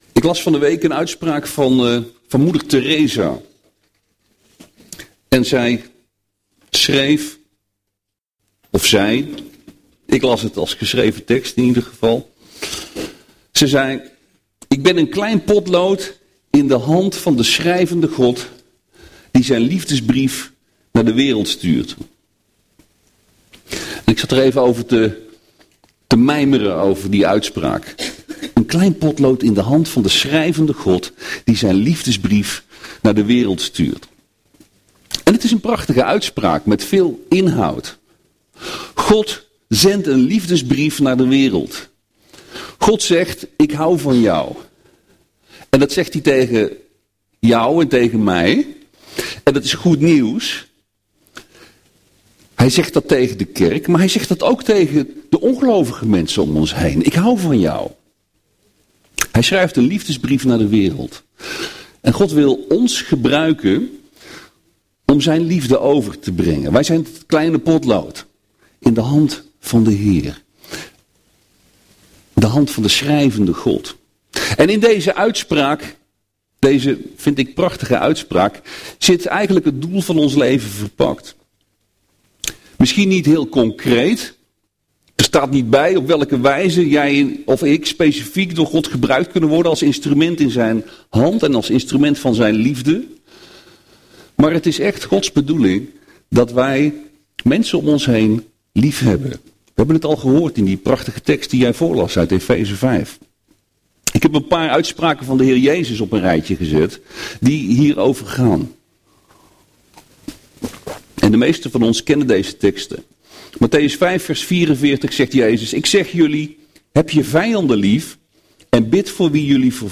Preken – Pagina 28 – Evangelische Kerk Jefta